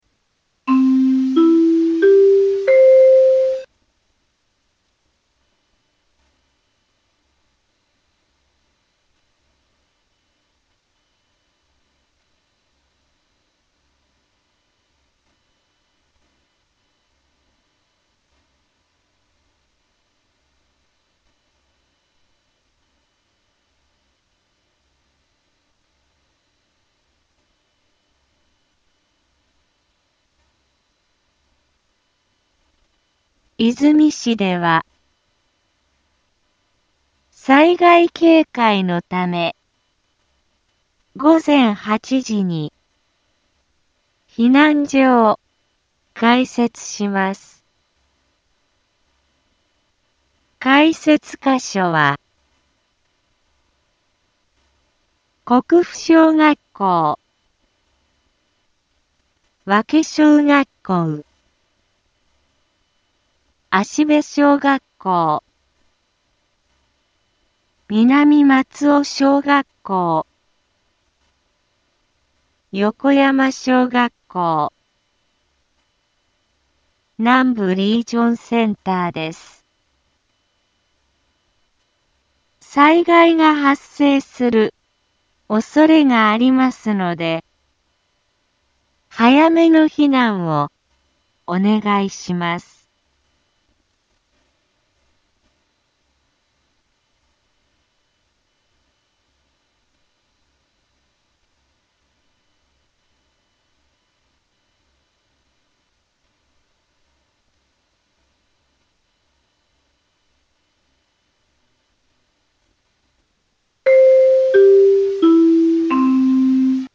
BO-SAI navi Back Home 災害情報 音声放送 再生 災害情報 カテゴリ：通常放送 住所：大阪府和泉市府中町２丁目７−５ インフォメーション：和泉市では、災害警戒のため午前８時に避難所を開設します。